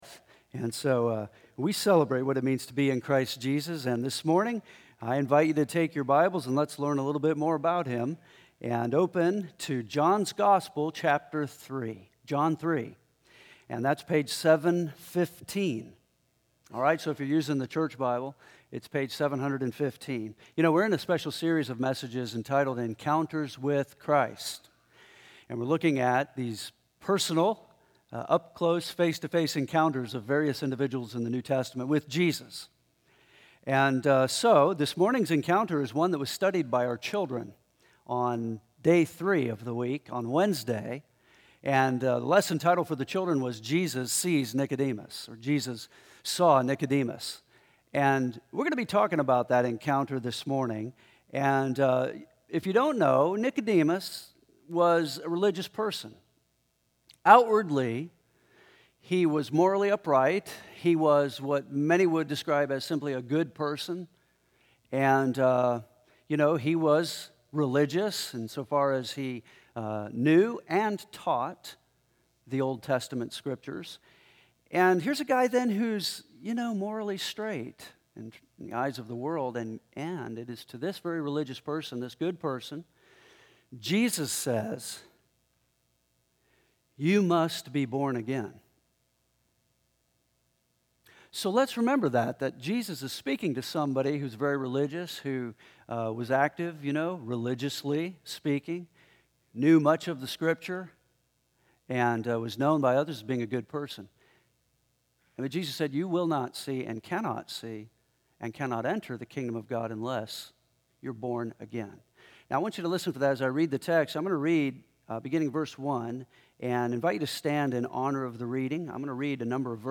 We are in a special series of messages entitled, “Encounters with Christ,” noting how various individuals came face-to-face with Jesus Christ.